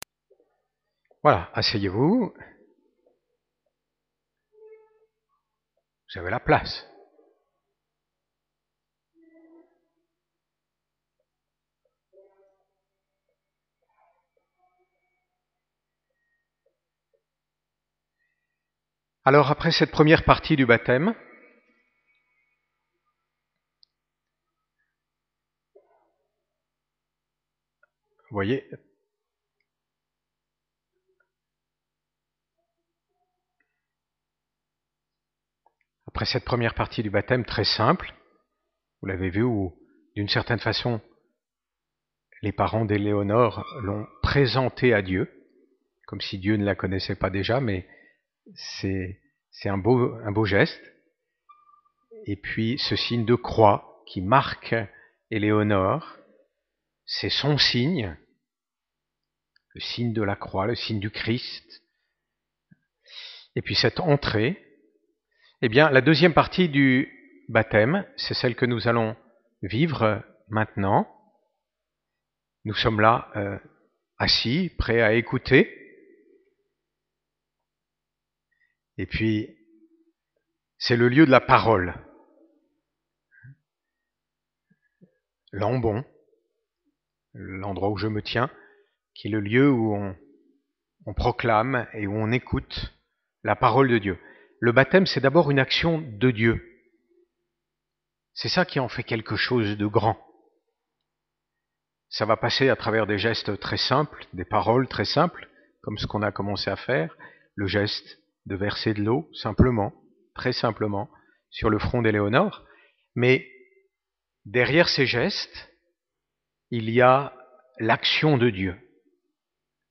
Evangile et homélie